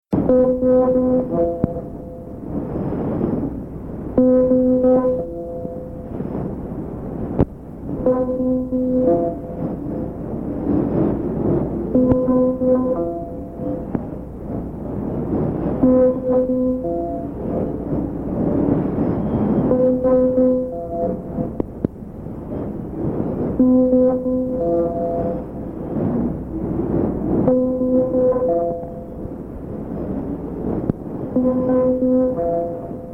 interval signals
documenting and reimagining the sounds of shortwave radio